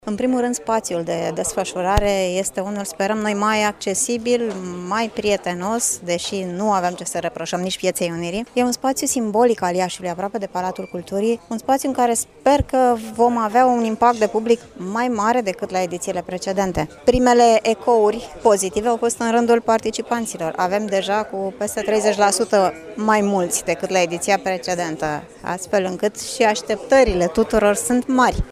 Peste 40 de edituri din țară sunt prezente, începând de astăzi, la Iași, la cea de-a treia ediție a Târgului de Carte „Gaudeamus – Radio România”.